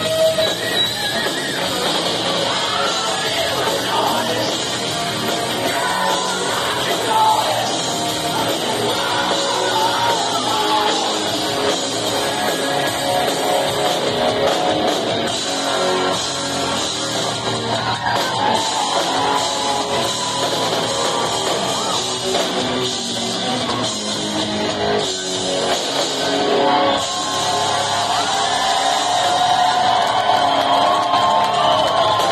vocalist
tribute band